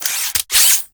Sfx_tool_spypenguin_grab_01.ogg